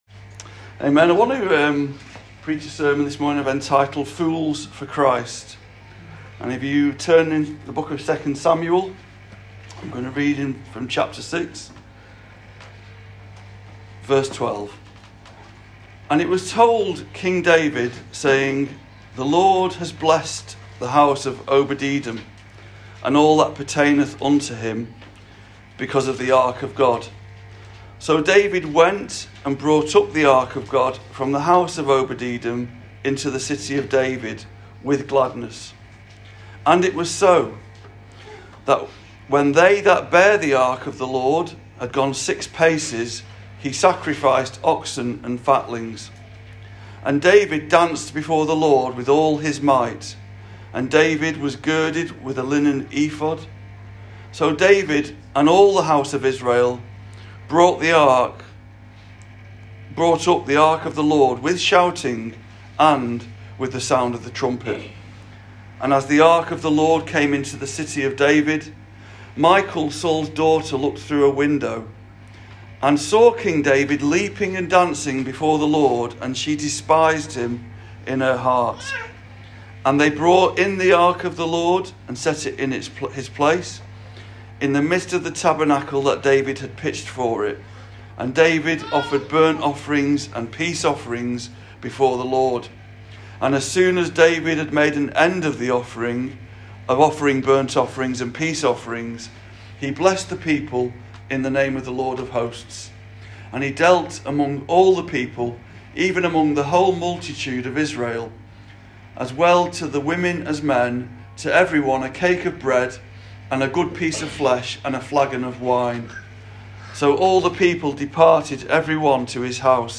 An episode by Calvary Chapel Warrington Sermons